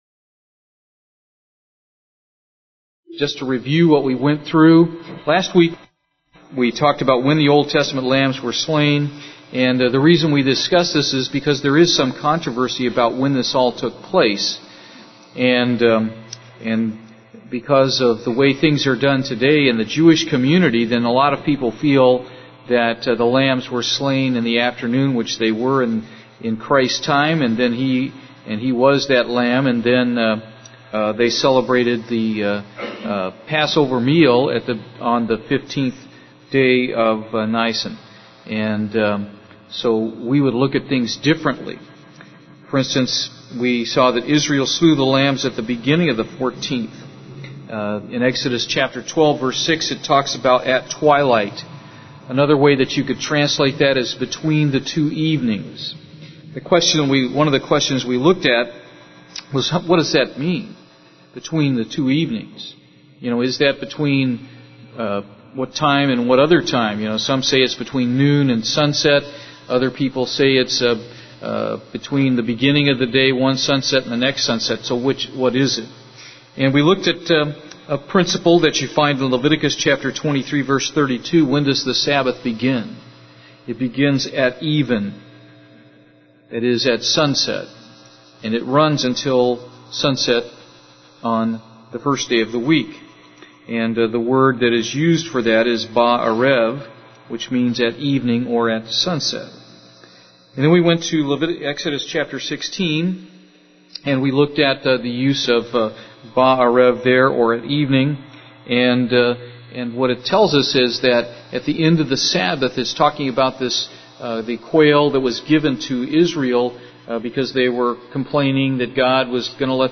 Given in Houston, TX
UCG Sermon Studying the bible?